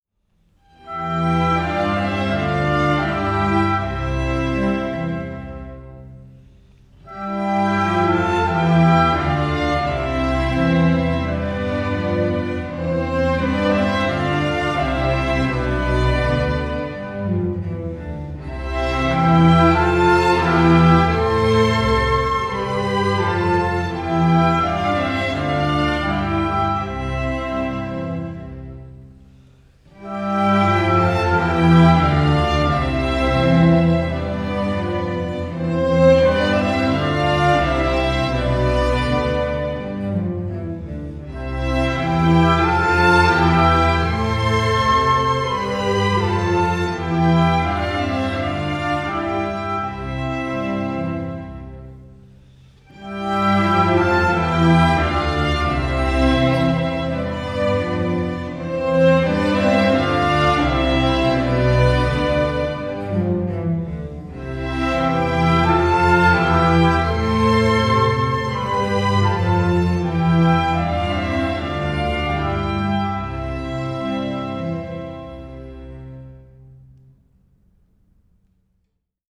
zum Mitsingen (rein instrumental)!